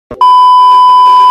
Radiotv Censor Beep Sound Sound Effects Free Download